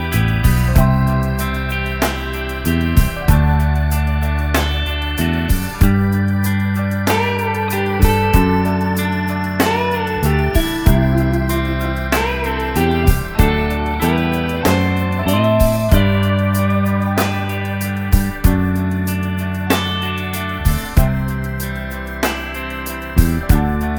no Backing Vocals Soft Rock 3:40 Buy £1.50